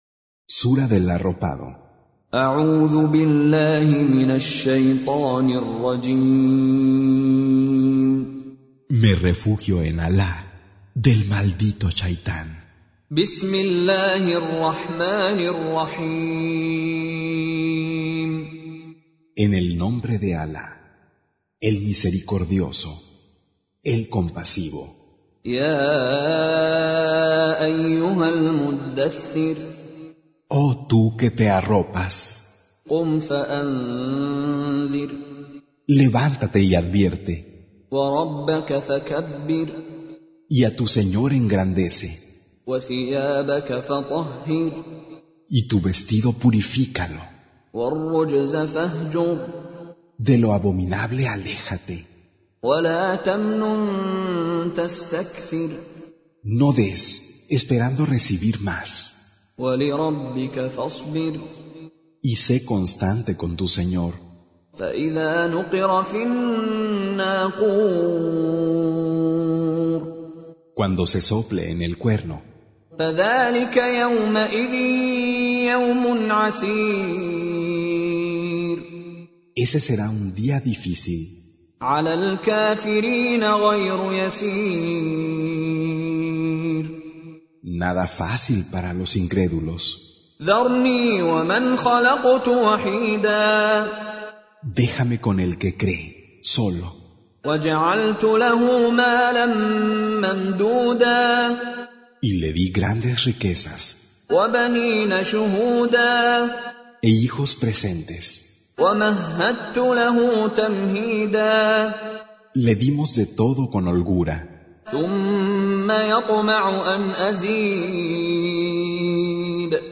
Recitation
Con Reciter Mishary Alafasi